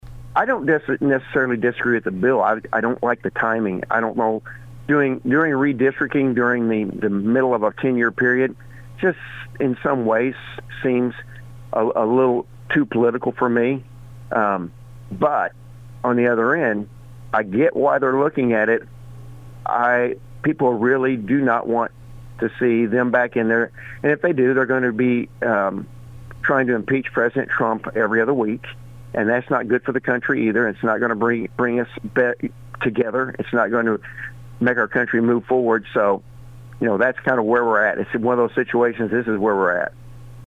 Missouri Senator Mike Henderson shared his perspective on the issue.